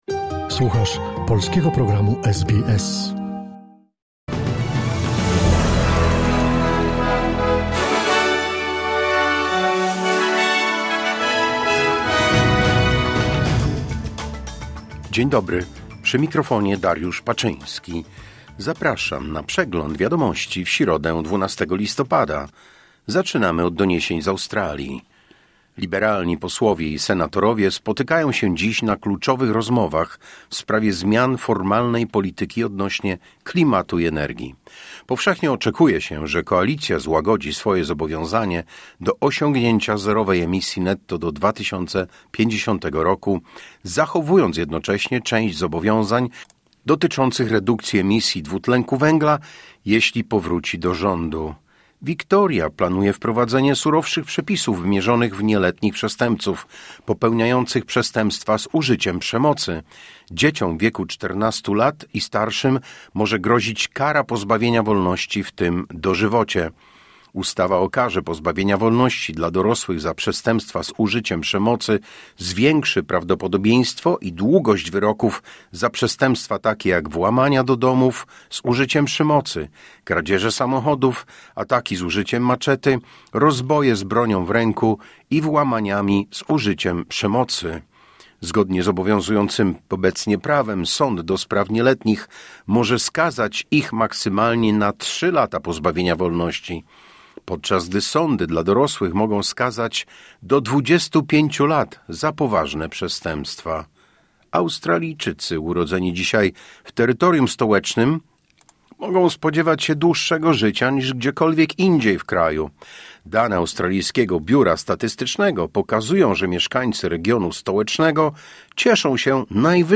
Wiadomości 12 listopada SBS News Flash